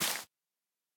Minecraft Version Minecraft Version snapshot Latest Release | Latest Snapshot snapshot / assets / minecraft / sounds / block / sponge / break4.ogg Compare With Compare With Latest Release | Latest Snapshot